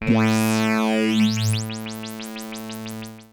/audio/sounds/Extra Packs/musicradar-synth-samples/Roland S100/Roland F Multi/
Roland F A2.wav